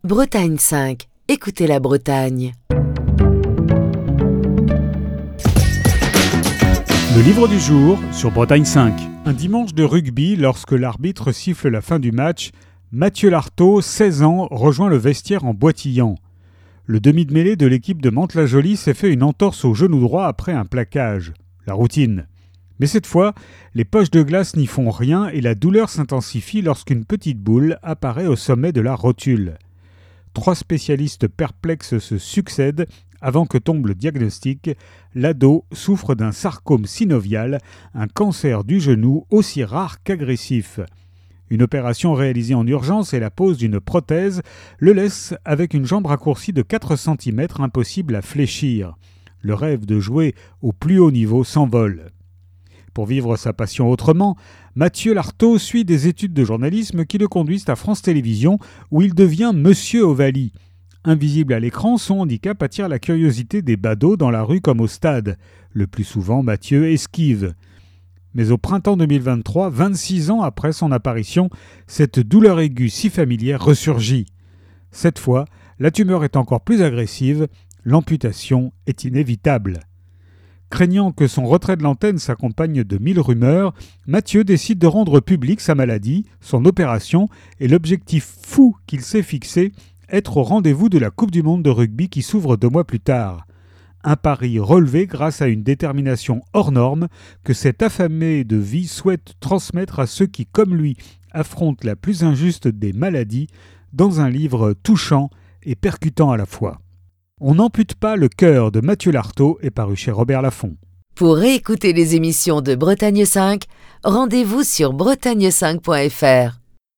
Chronique du 23 avril 2024.